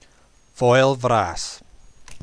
To hear how to pronounce Foel Fras, press play: